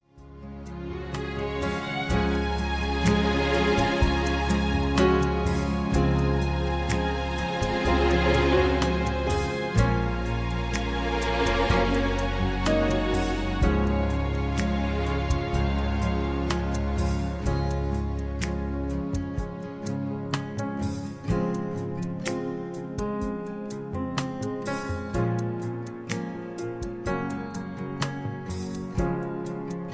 (Key-Cm, Tono de Cm) Karaoke MP3 Backing Tracks